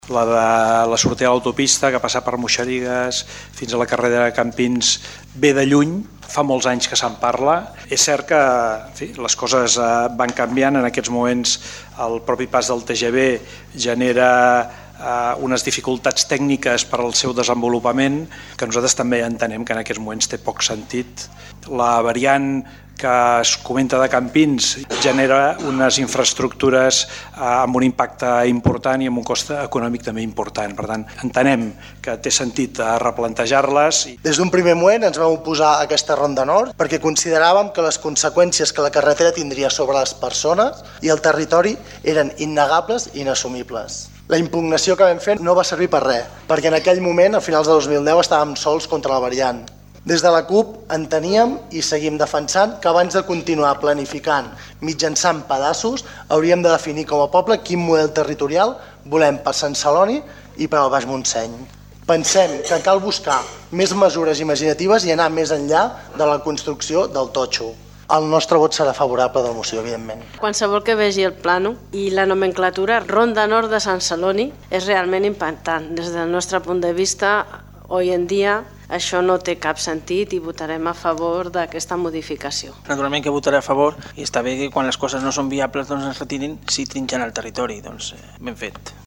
Fragments d'àudio de les intervencions del diferents representants dels grups municipals:
Joan Castaño (alcalde, PSC)
Francesc Deulofeu (CiU), Dani Corpas (CUP), Carmen Montes (ICV) i Manel Bueno (no adscrit)